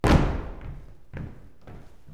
112 STOMP1-R.wav